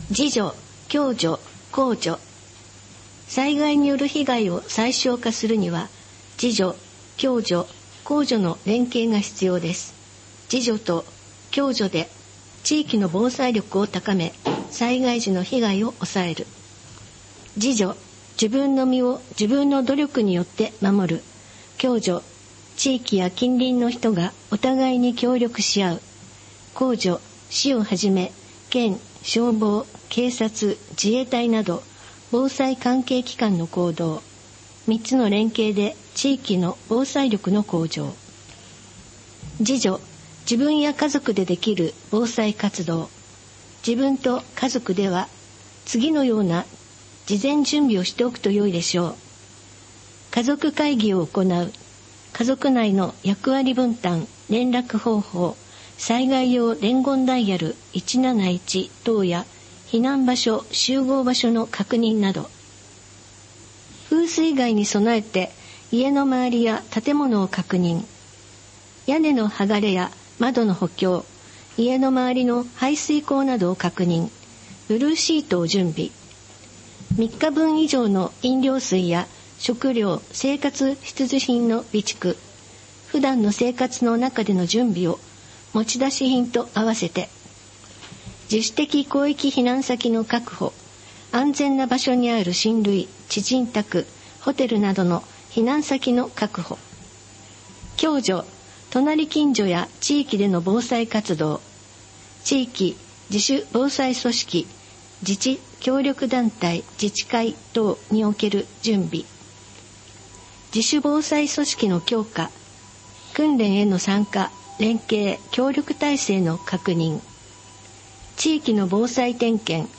【協力】録音：加須市朗読ボランティア「やよい」編集：加須市社会福祉協議会